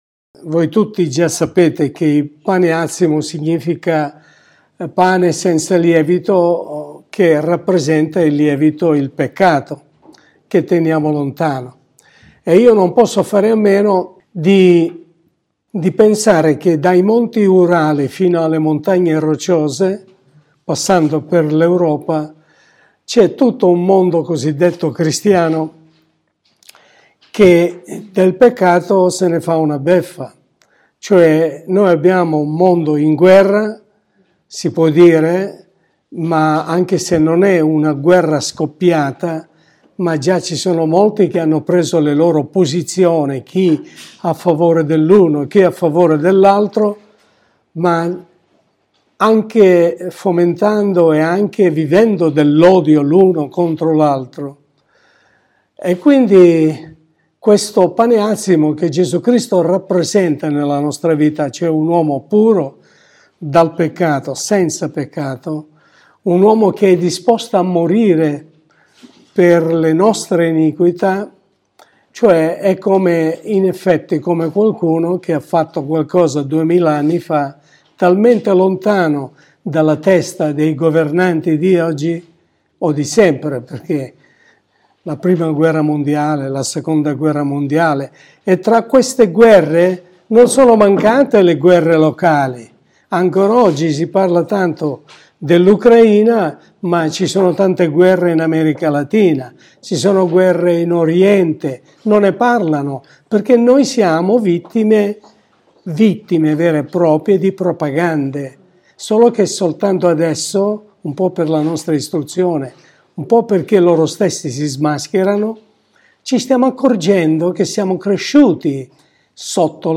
Sermone pastorale
Primo giorno dei Pani Azzimi